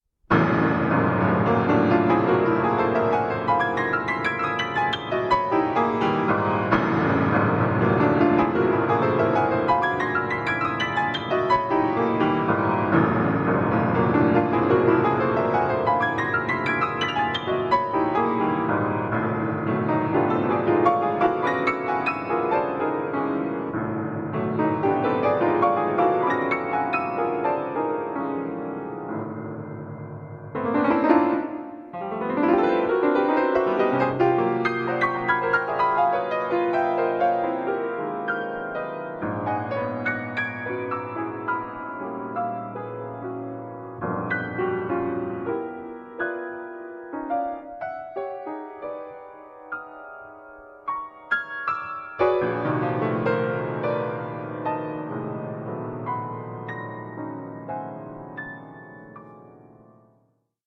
Stereo
piano (archival recording)